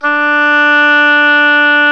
WND OBOE D4.wav